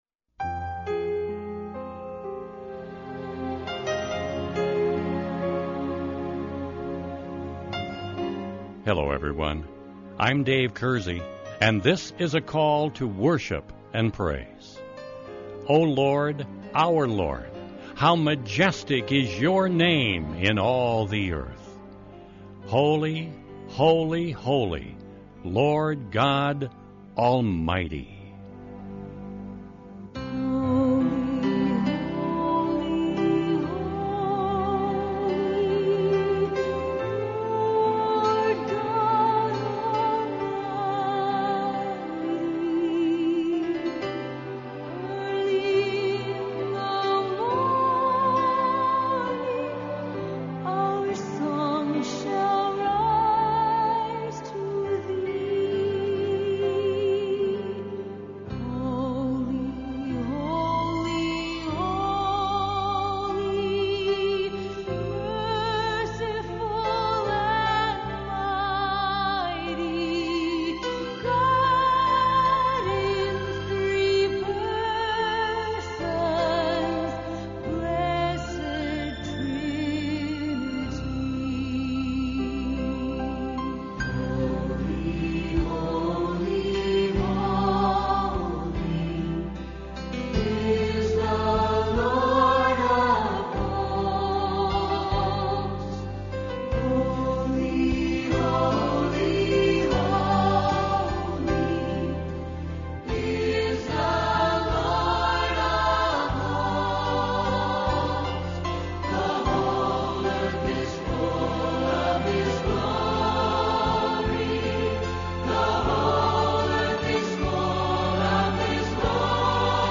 This week on Call To Worship we are going to share Psalm 8 and then build our entire program of music and thoughts around the Majesty and creative Glory of Almighty God.